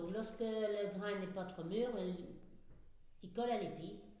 Localisation Falleron
Catégorie Locution